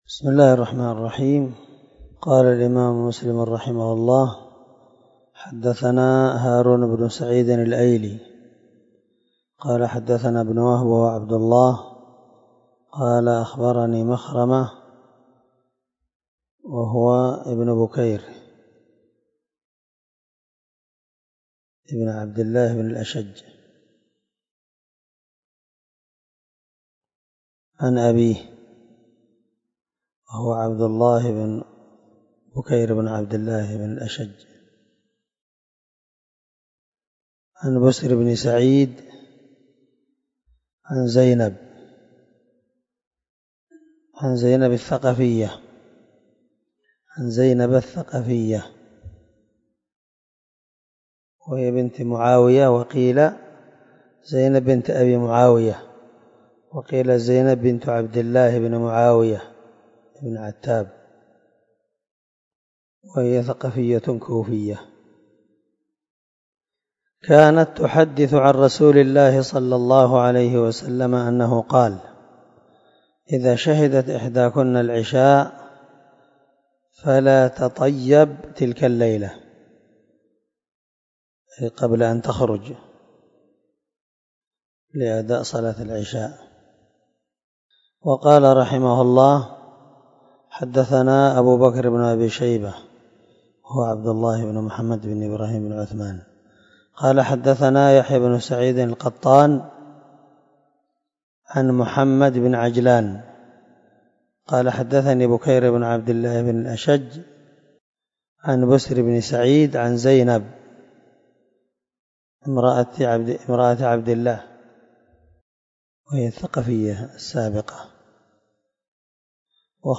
300الدرس 44 من شرح كتاب الصلاة حديث رقم ( 443 – 447 ) من صحيح مسلم